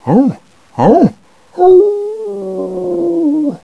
1 channel
howl3.wav